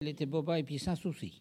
Localisation Saint-Urbain
Catégorie Locution